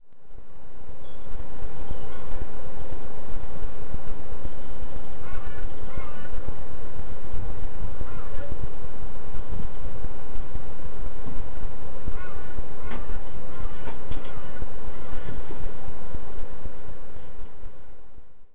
zgb-crow-F-sharp-D-F-D-earlier.wav